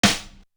Intrude Snare.wav